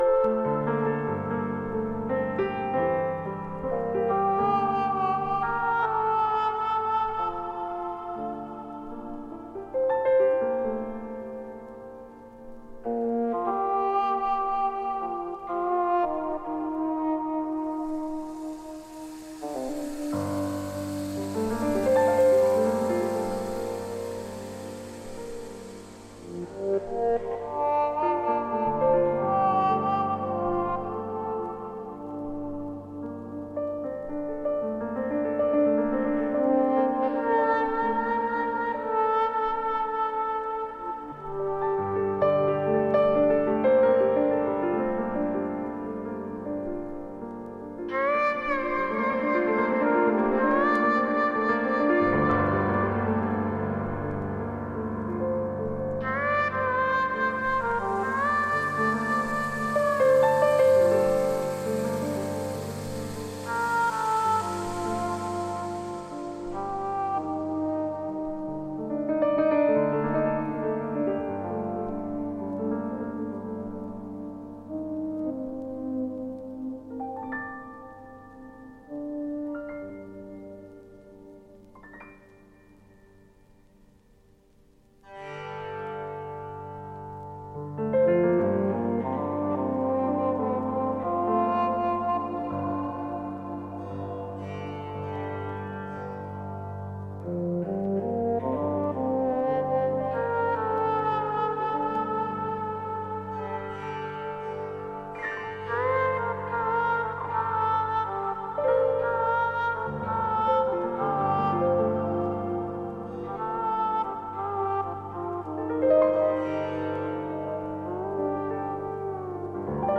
House
Jazz